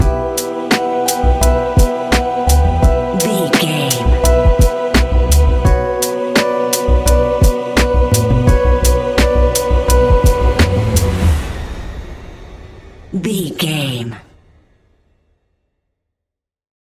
Ionian/Major
B♭
laid back
Lounge
sparse
new age
chilled electronica
ambient
atmospheric